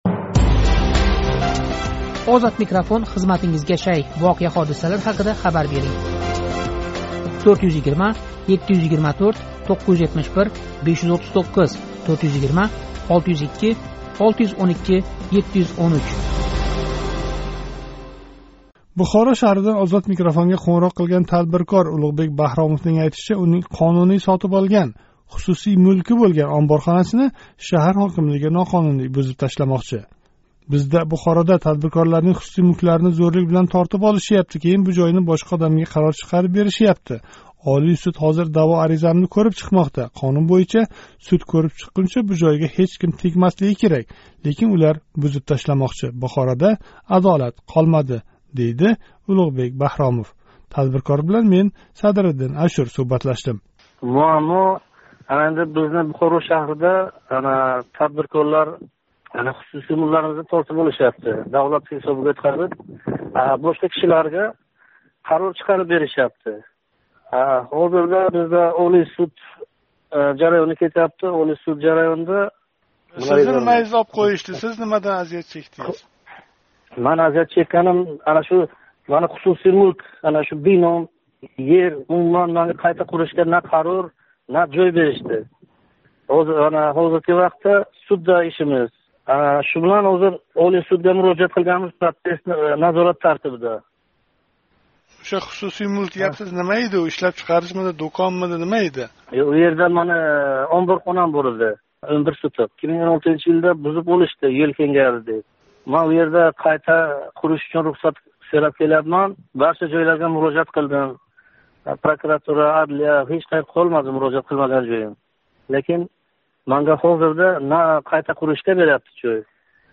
Бухоро шаҳридан OzodMikrofonга қўнғироқ қилган тадбиркор